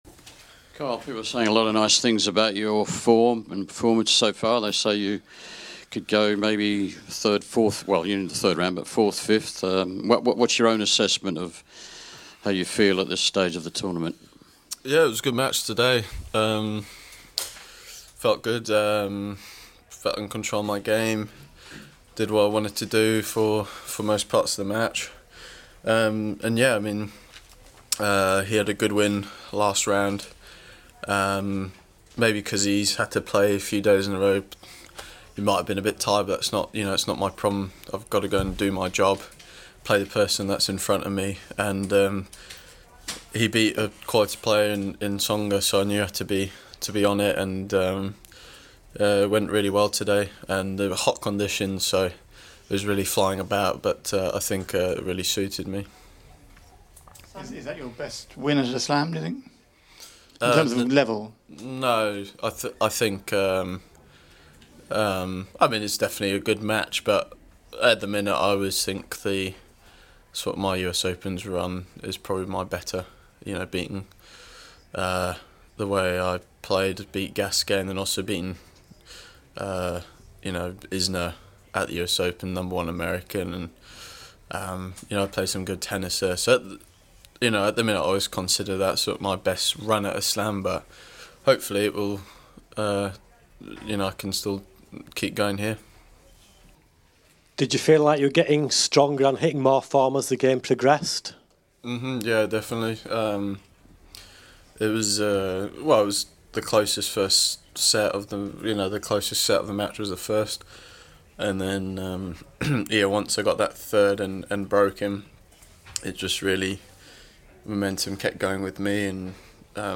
Here's Kyle after his 7-5, 6-3, 6-1 win vs Renzo Olivo in the 2nd round at Roland-Garros